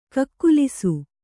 ♪ kakkulisu